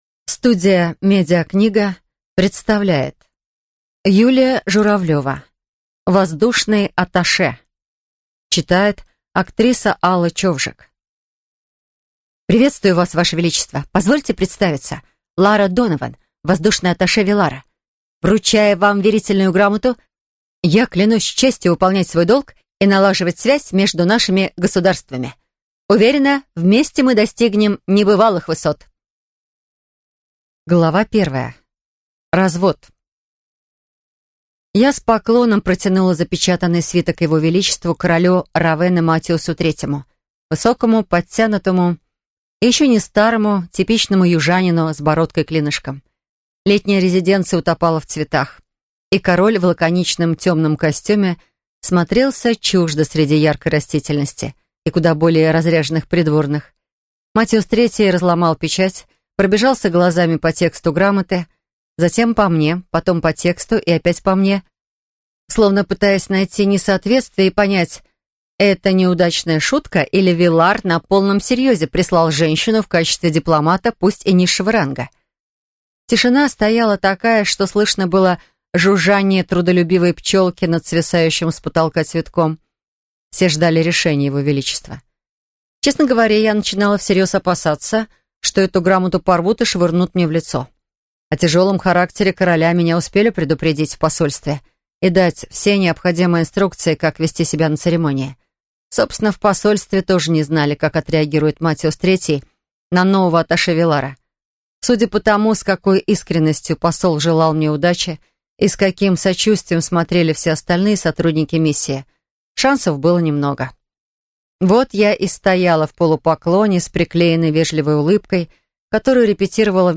Аудиокнига Воздушный атташе | Библиотека аудиокниг